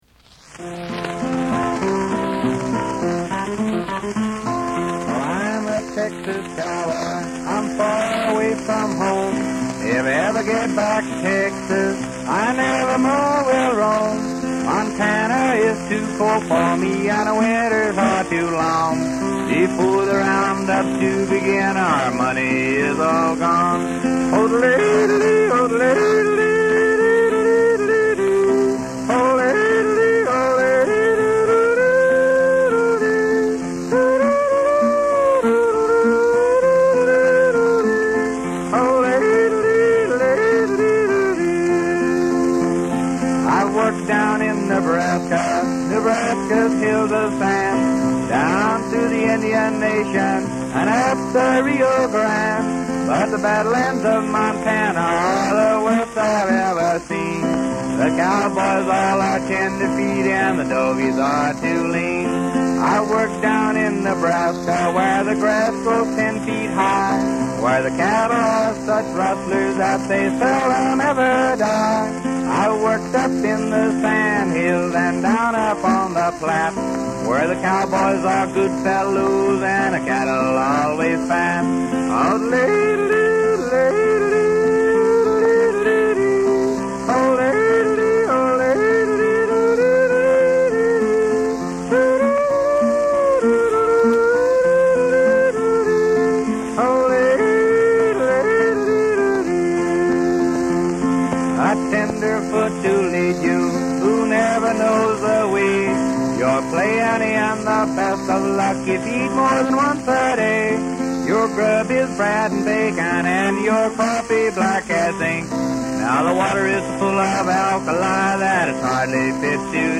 yodels